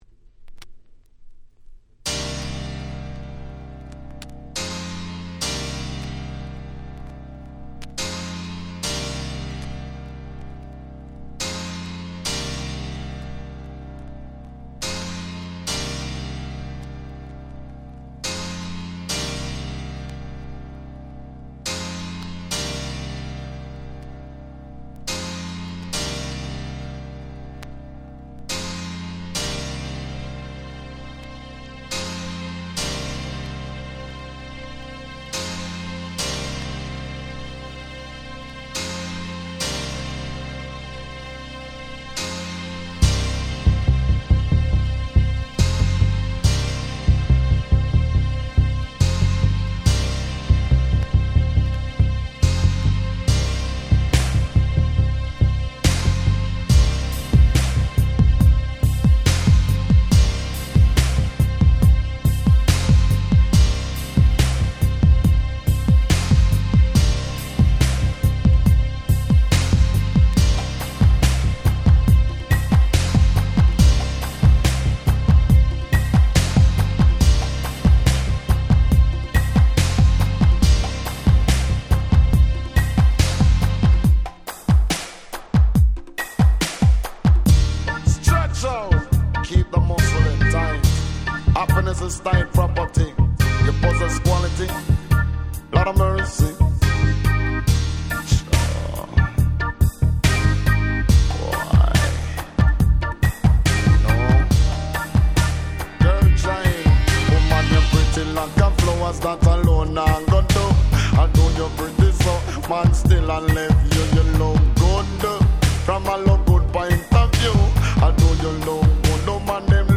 緩〜いBeatのまったりとした名曲！
人気の90's Reggaeです！